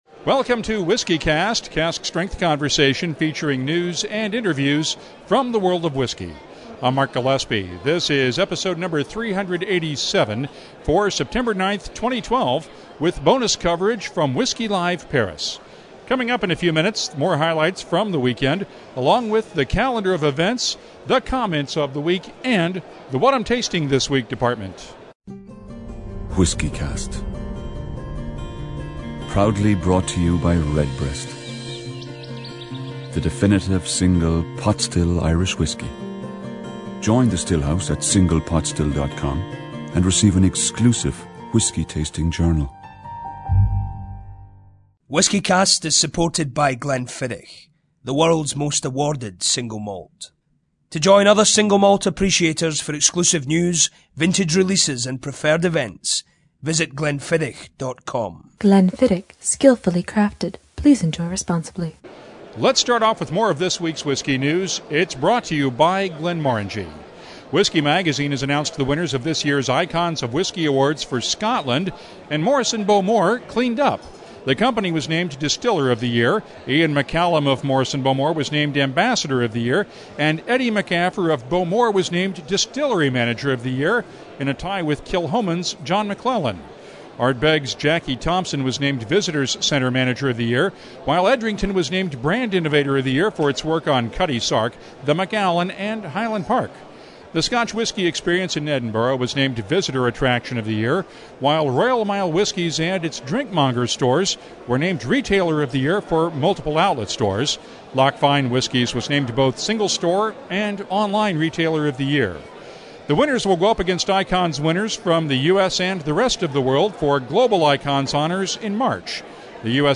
More from Whisky Live Paris in this special episode